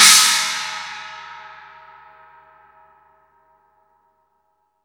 Index of /90_sSampleCDs/East Collexion - Drum 1 Dry/Partition C/VOLUME 004
CHINA02.wav